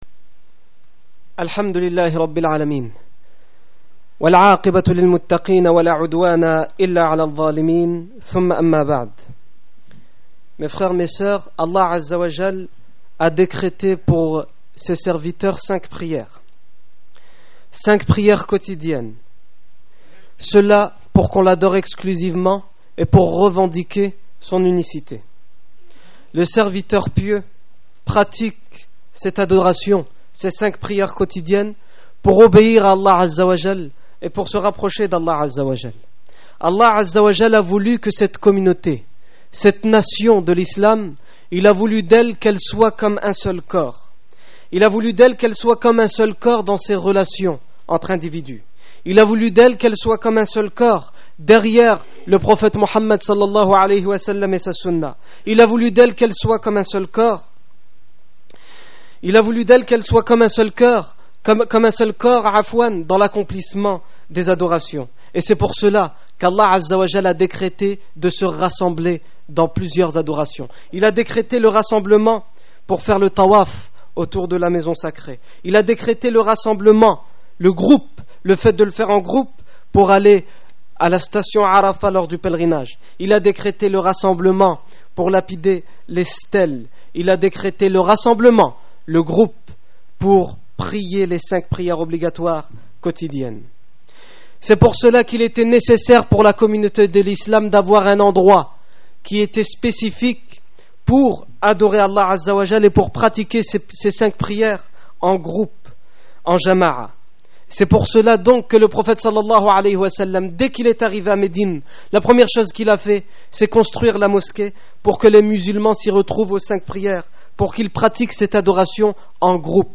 Discours du 10 avril 2009
Discours du vendredi